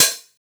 edm-hihat-25.wav